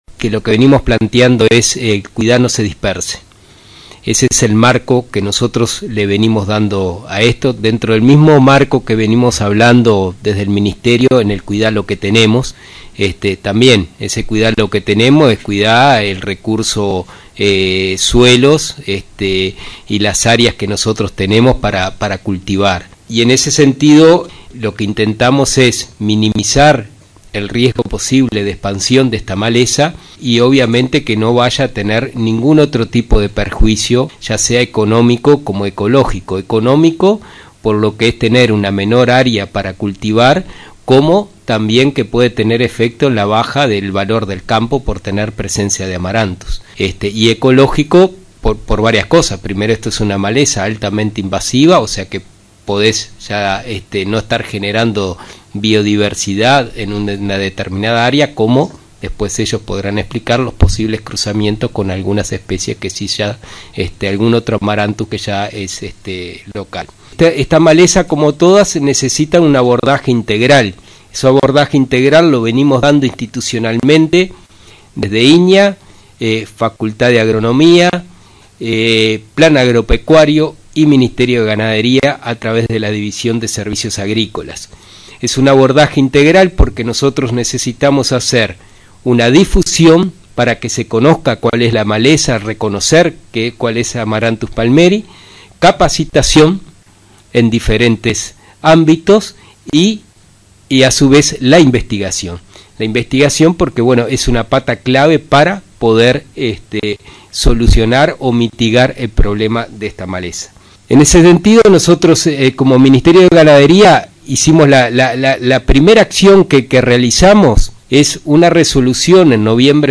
El Ministerio de Ganadería, Agricultura y Pesca exhorta al control de la maleza Amaranthus palmieri, que afecta valor de campos y disminuye áreas de cultivo para soja, trigo o maíz. Se resolvió que toda maquinaria usada que ingrese al país cuente con un certificado oficial que garantice la no presencia. Además realiza talleres para capacitar a técnicos y productores, informó el director de Servicios Agrícolas, Federico Montes.